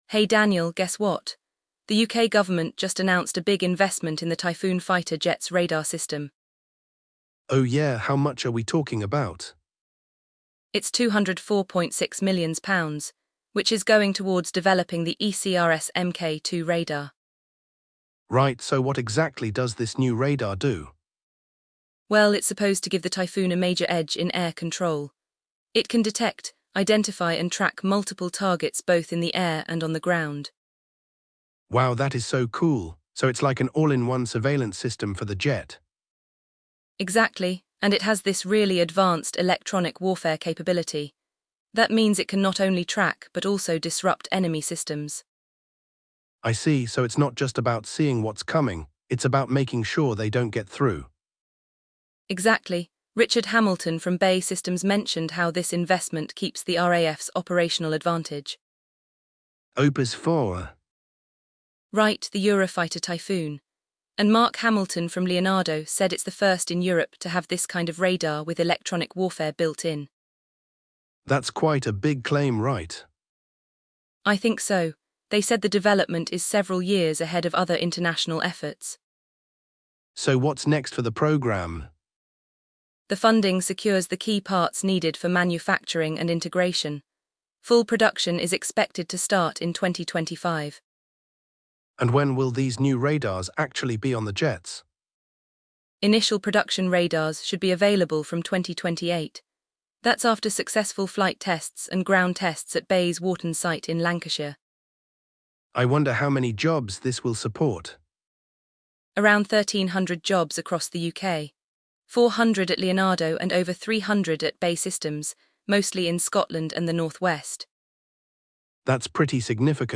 The conversation also explores the economic impact, highlighting the 1,300 jobs supported across the UK and the broader economic ripple effect.